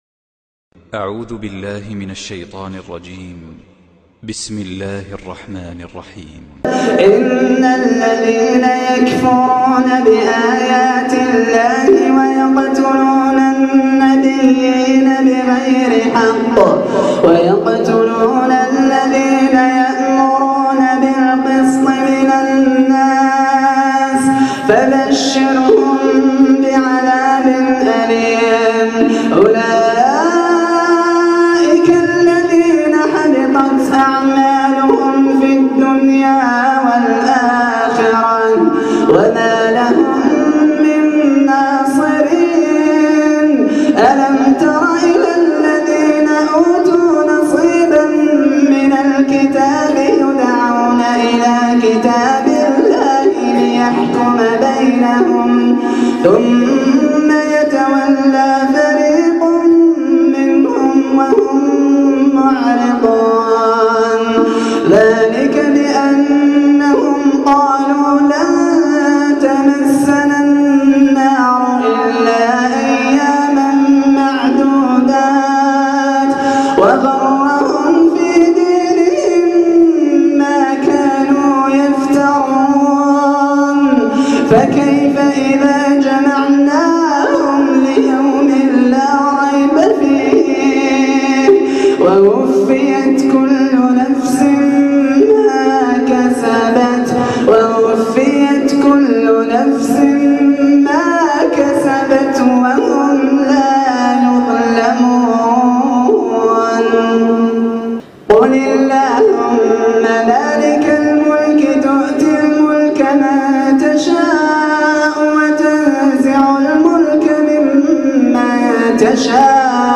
استمع لأجمل تلاوة بصوت عذب وخاشع